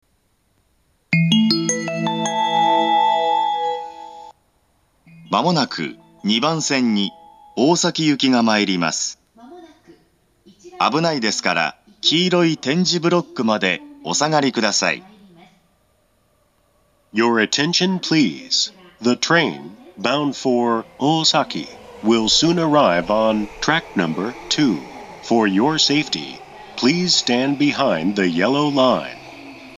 ２番線接近放送